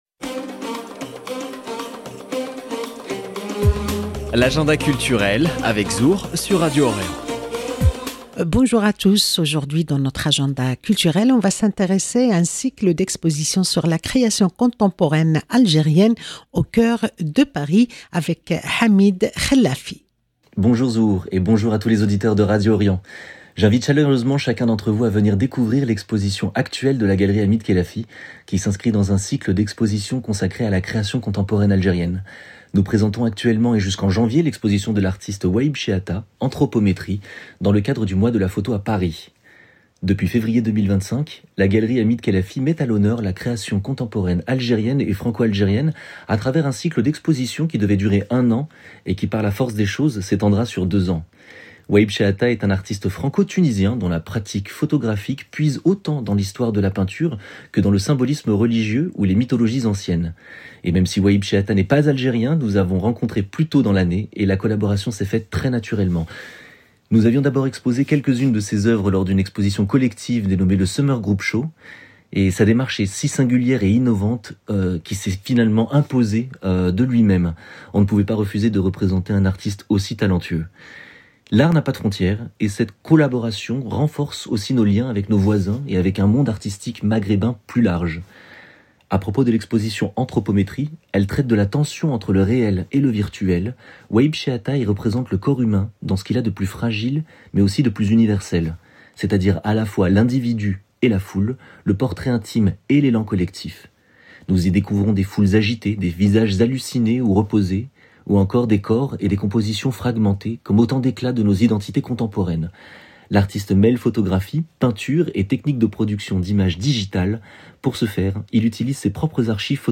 AGENDA CULTUREL